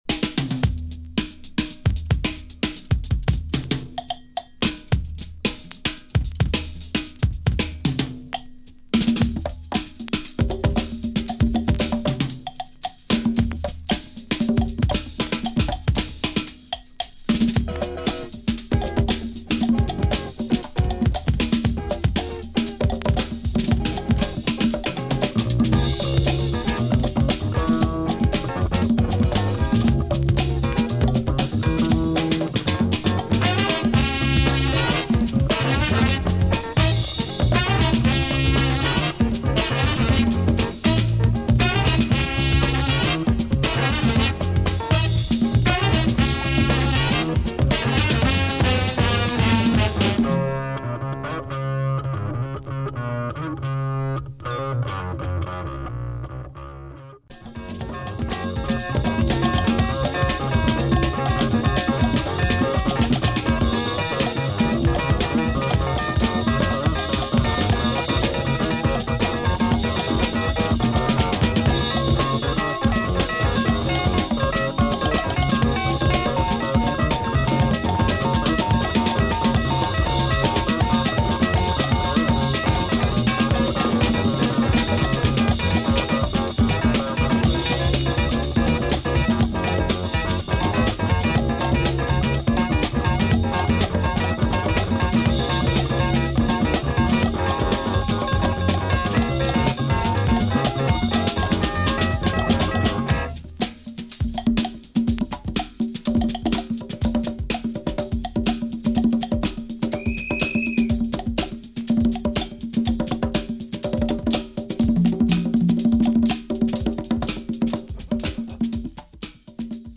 Excellent funky jazz & fusion from Hungary.